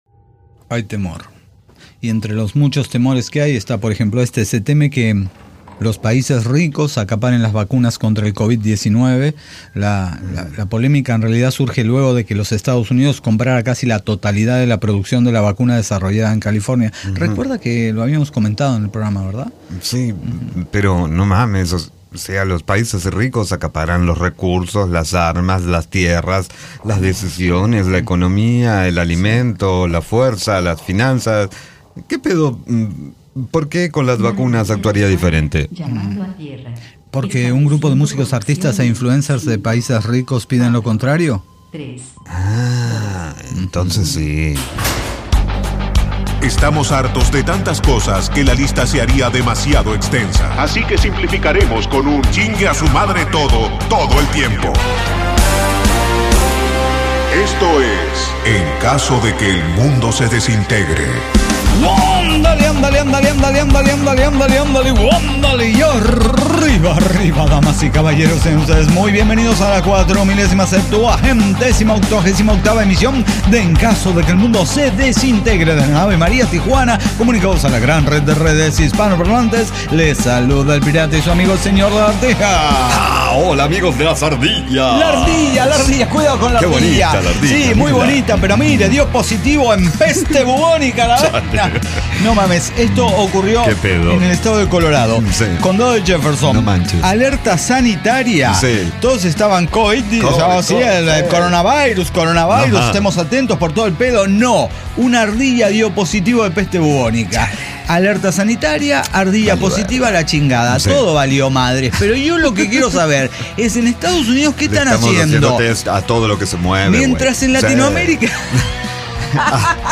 Las historias más diversas de fiestas narradas por la misma voz de los desintegrados. Protagonistas y testigos de celebraciones que dejaron huella.